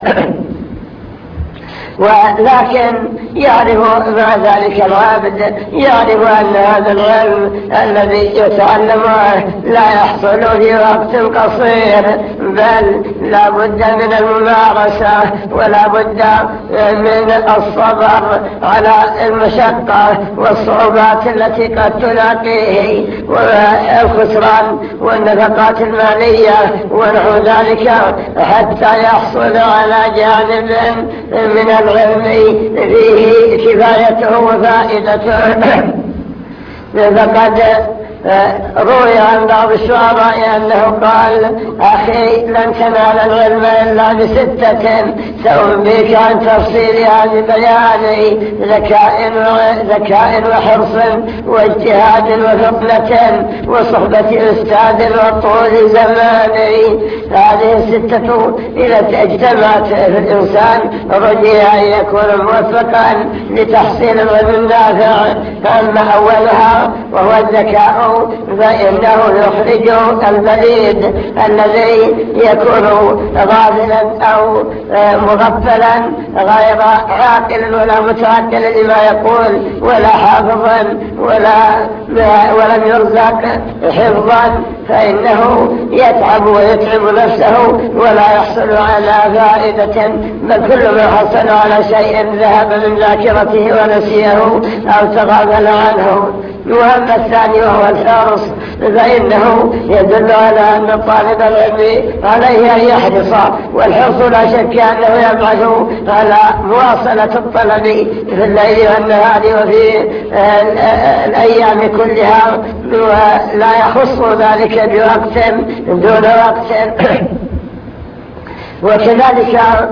المكتبة الصوتية  تسجيلات - محاضرات ودروس  محاضرات عن طلب العلم وفضل العلماء أهمية التفقه في الدين وكيفية تحقيق معنى العبودية